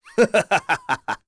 Chase-Vox_Happy2.wav